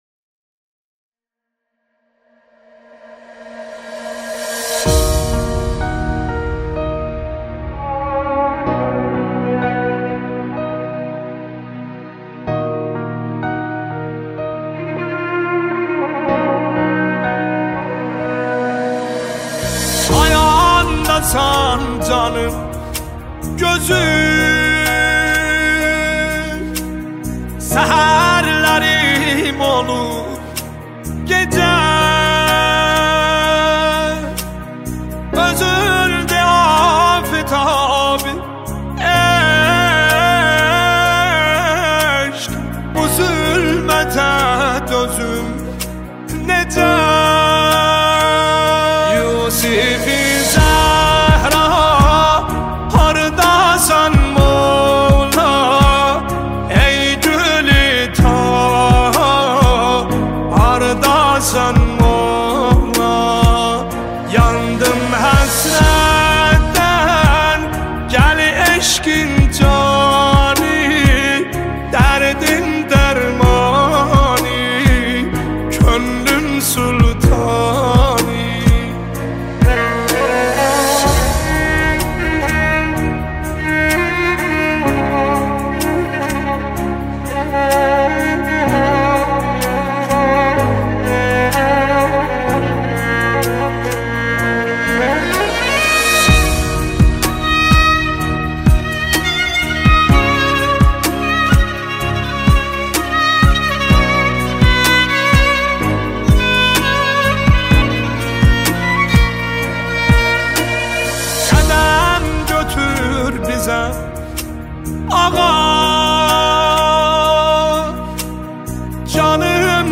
نماهنگ ترکی دلنشین و احساسی